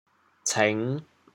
潮阳拼音“cêng5”的详细信息
cêng5.mp3